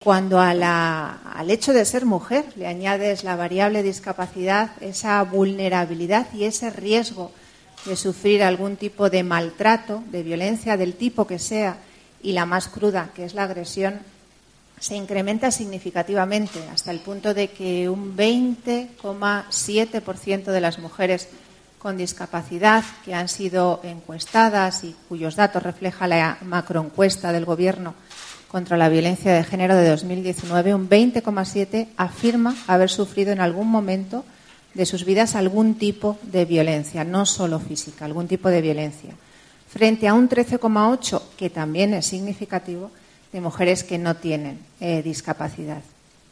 Por un lado, el salón de actos de la Dirección General de la ONCE en Madrid acogió el acto institucional ‘El amor.. ¿es ciego?’Abre Web externa en ventana nueva, con el que la Organización se sumó a este Día.